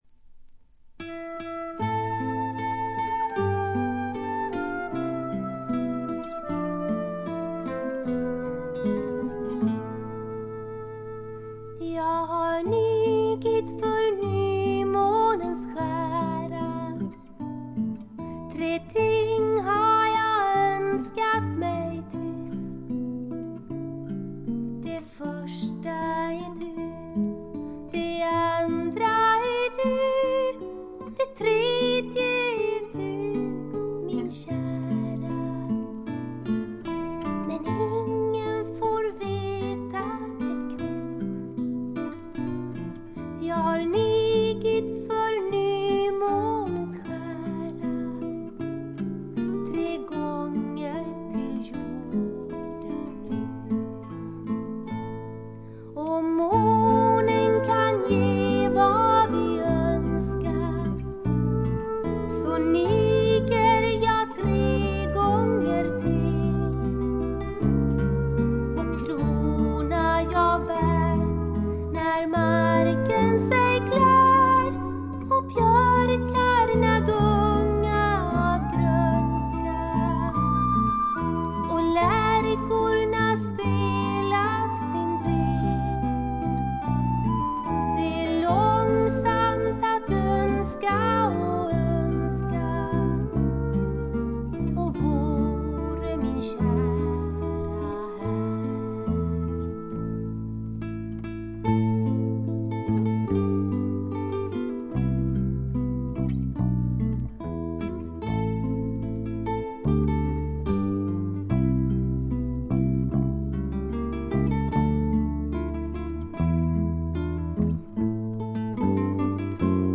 軽微なプツ音が2回ぐらい出たかな？という程度でほとんどノイズ感無し。
ちょっとポップなフォーク・ロックも素晴らしいし激渋のトラッドも良いです。
試聴曲は現品からの取り込み音源です。